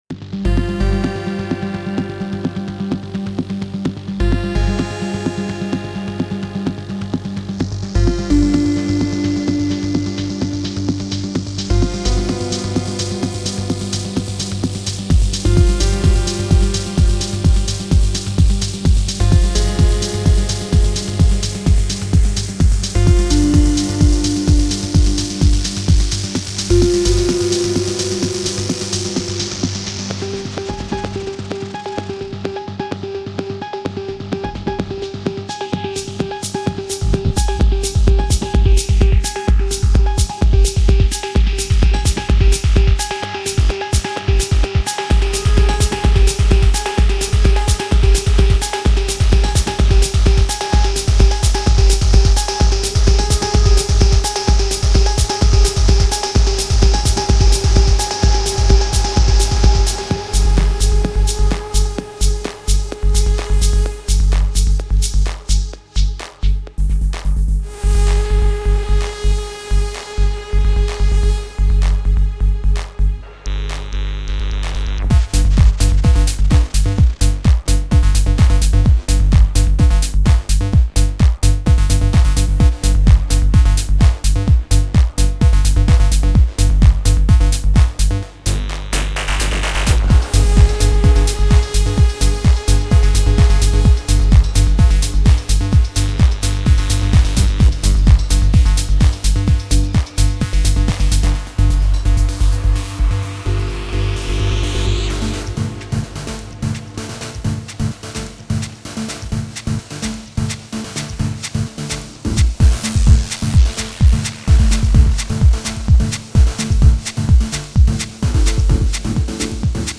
techno patches for pigments synthesizer
Delivering an inspiring toolbox for deep, melodic, and ethereal techno music production.
In detail, the soundbank contains: 22 Bass, 10 Keyboard, 22 Lead, and 10 Pad presets.
Listen to a selection of the sounds included inside the full product. No additional or external processing is applied to the sounds.